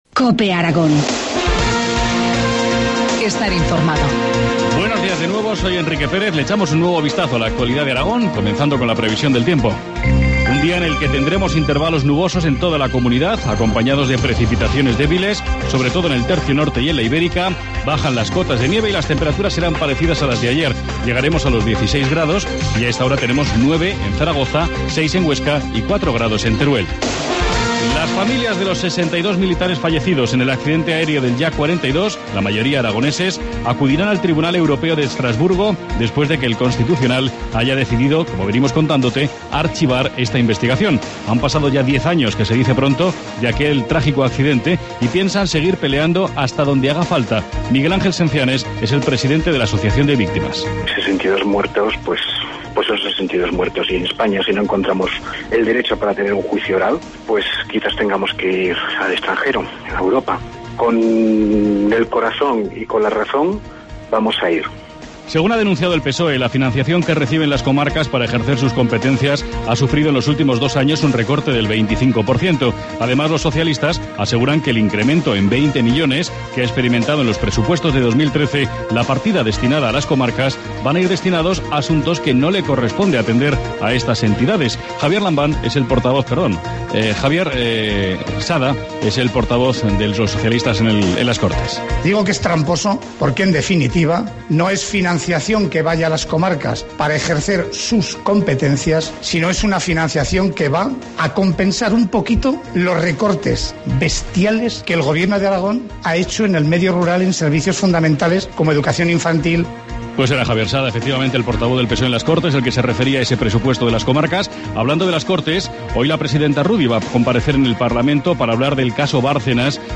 Informativo matinal, jueves 21 de febrero, 8.25 horas